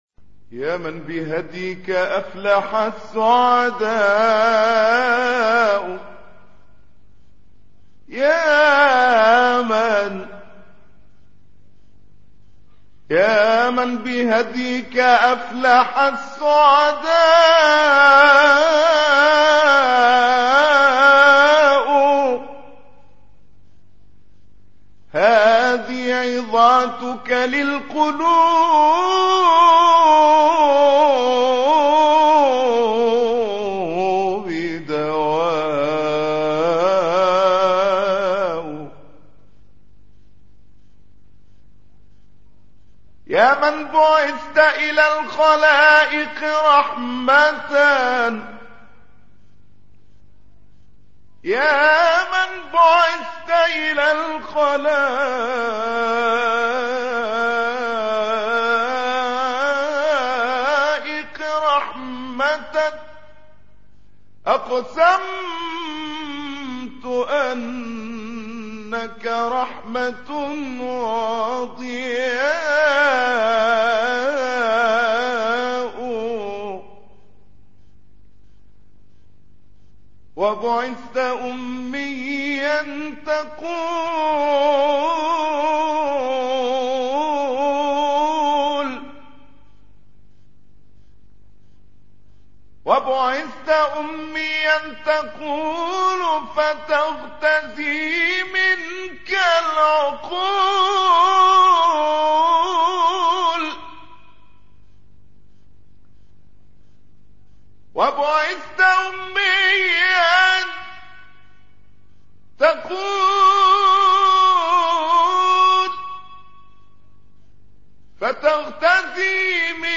أناشيد